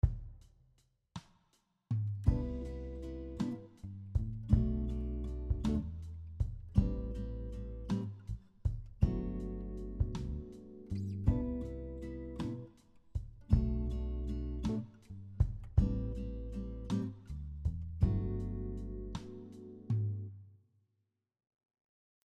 Here are some musical examples using shell chords including tabs and audio.
Shell chord progression example 2
Here is the same chord progression as the previous except we shift the chords to the key of G Major.